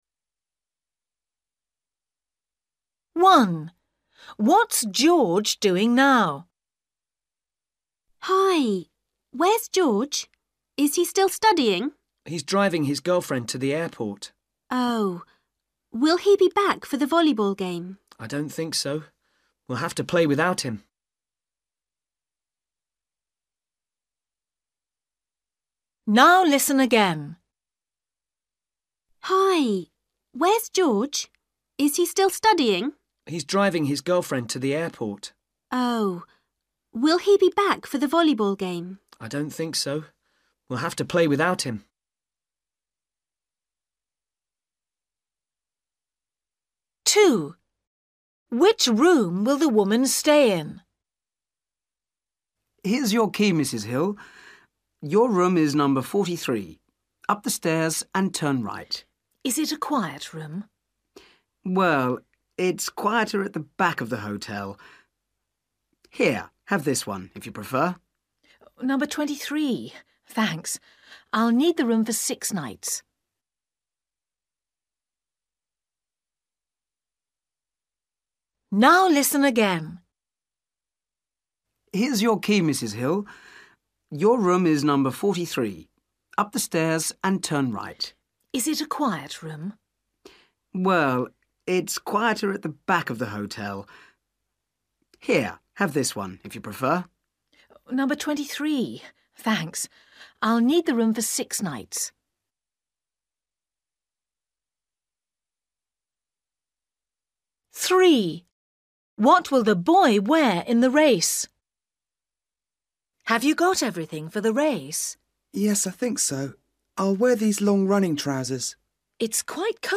You will hear five short conversations.
You will hear each conversation twice.